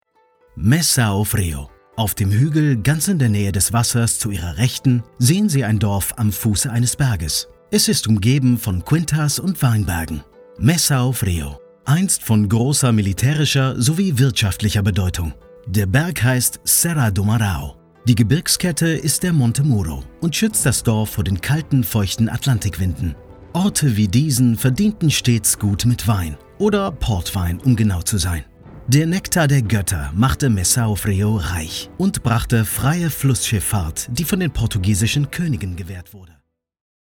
Audioguides
Ayant grandi bilingue, parlant un ALLEMAND et un ANGLAIS britannique parfaitement neutres, je suis le collaborateur idéal pour un travail vocal qui nécessite une prestation fiable, claire, assurée, naturelle et chaleureuse.
Sennheiser MK4
Cabine insonorisée